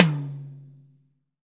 TOM TOM 97.wav